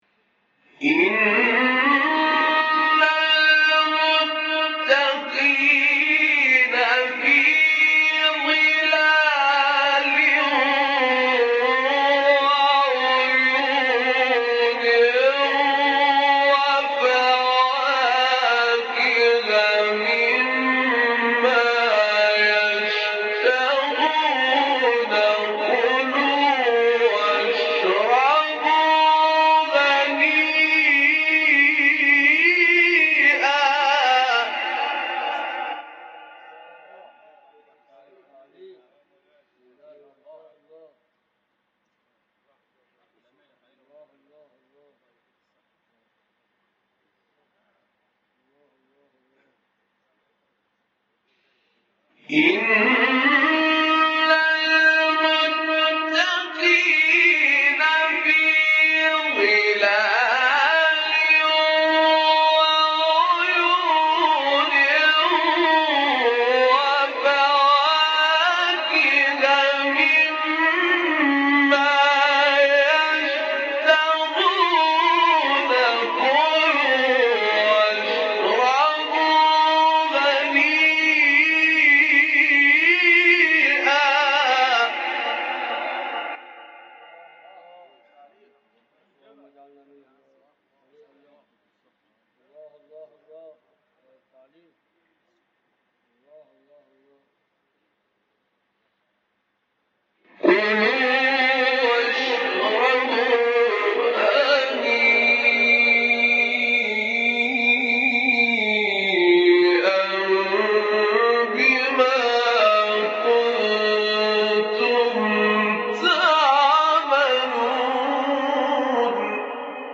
تلاوت سوره مرسلات و نبا با صدای استاد طاروطی | نغمات قرآن | دانلود تلاوت قرآن